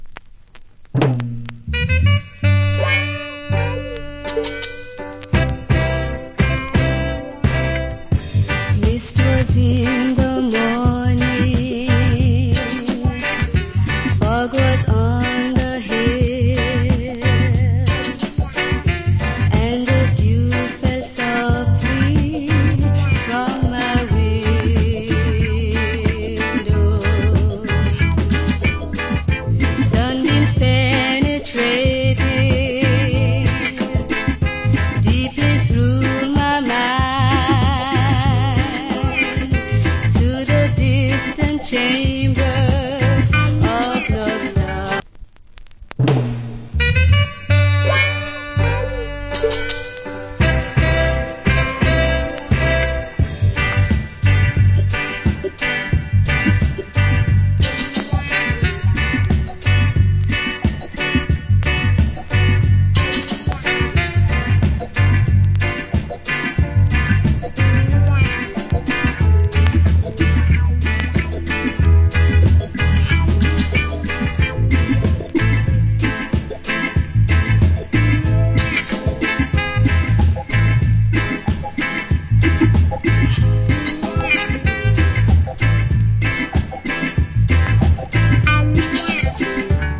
Good Female Reggae Vocal.